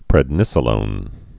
(prĕd-nĭsə-lōn)